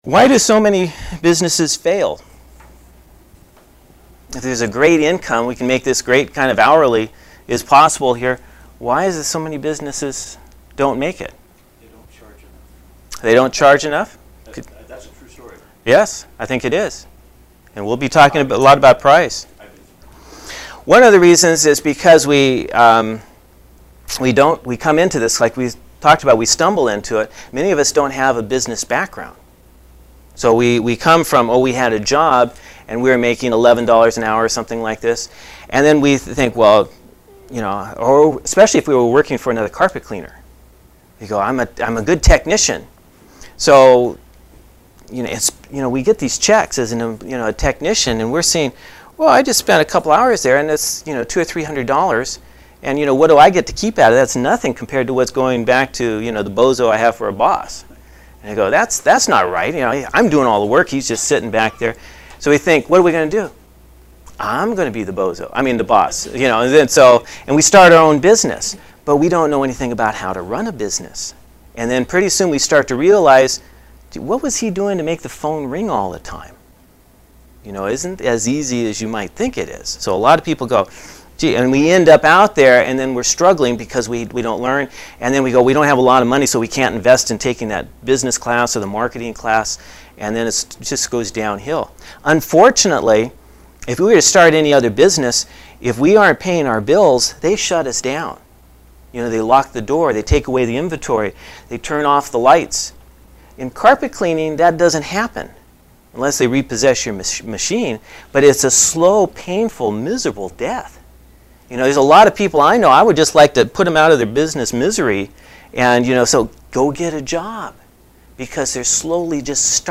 Single Truck Success Audio Seminar